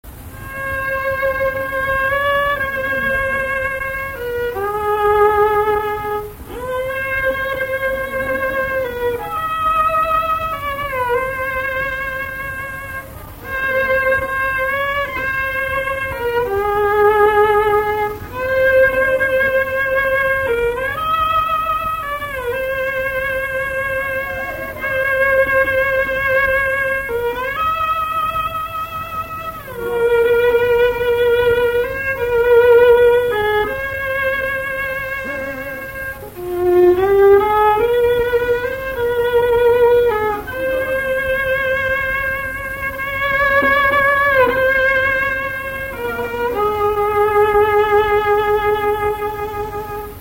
Enchaînement de plusieurs thèmes musicaux
Pièce musicale inédite